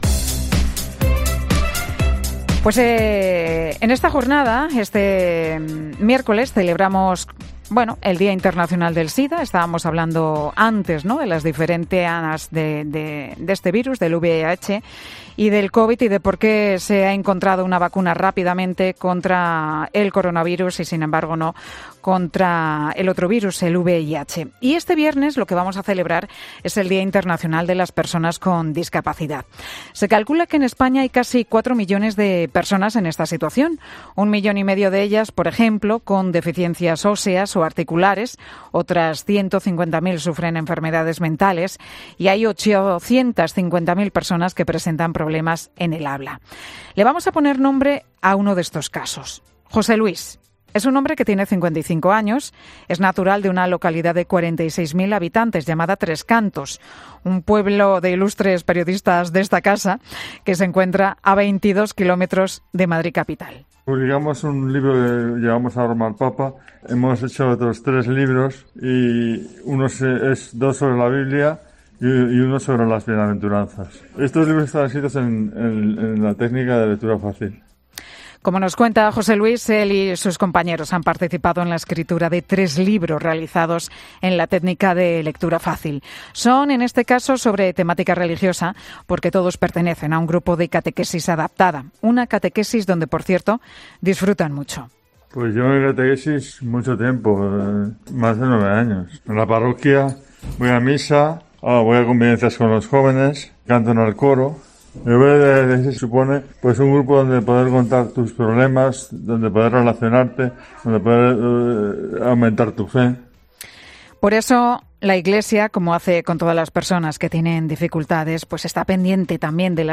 Hablamos en 'Mediodía COPE' con una catequista de Tres Cantos que ha elaborado materiales adaptados